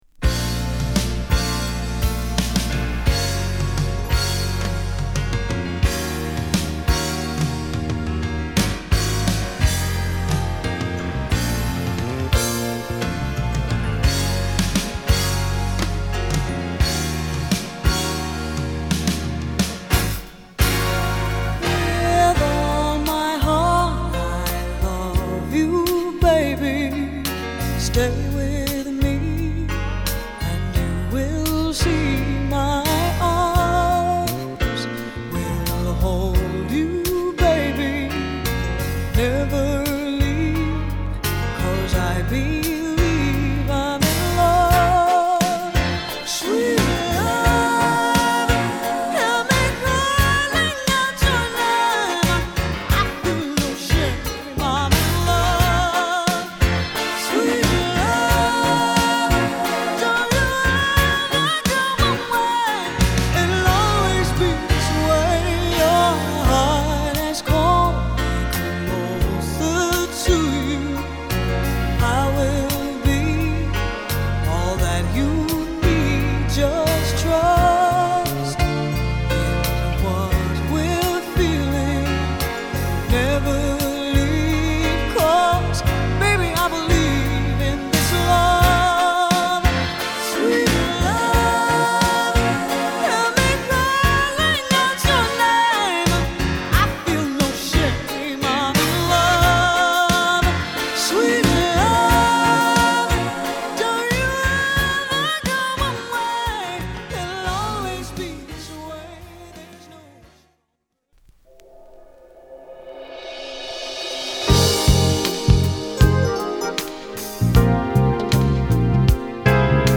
デトロイト育ちのシンガー
アルバム通してもこの時代ならではのメロウな感じが堪りません。